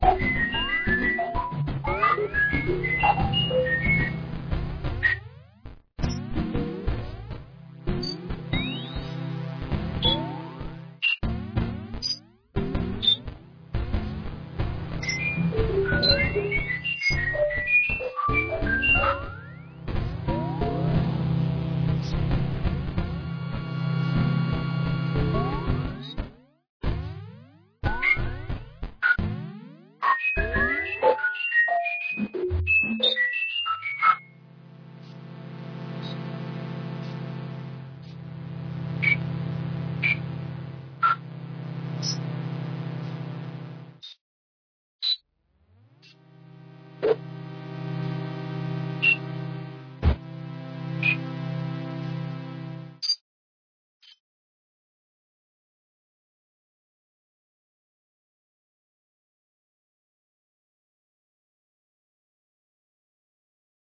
Synthesizer
Robotic sci-fi sounds.
32kbps-triond-synthesizer.mp3